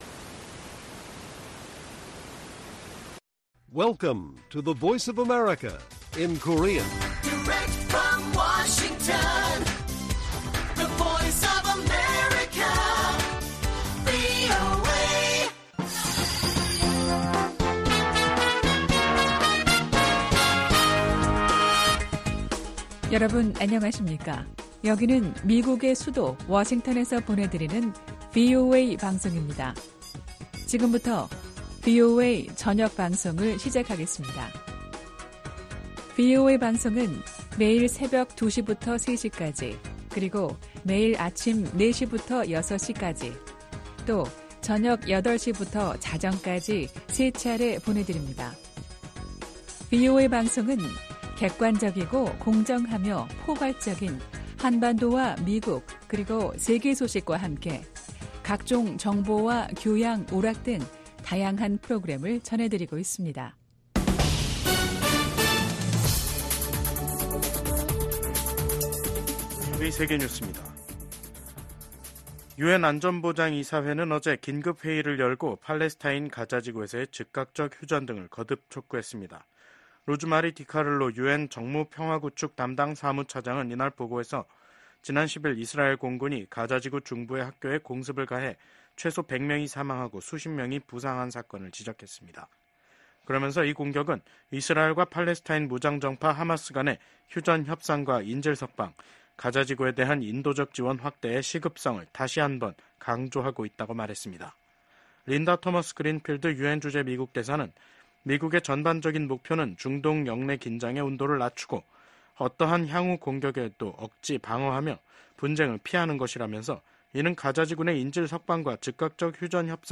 VOA 한국어 간판 뉴스 프로그램 '뉴스 투데이', 2024년 8월 14일 1부 방송입니다. 북한이 개성공단 내 철도 부속 건물 2개 동을 해체했습니다. 미 국무부는 북한이 러시아의 전쟁 수행을 지원하는 것을 좌시하지 않겠다고 밝혔습니다. 미국 국방부는 한국군의 전략사령부 창설 추진과 관련해 미한 동맹을 강조하며 긴밀하게 협력해 나갈 것이라고 밝혔습니다.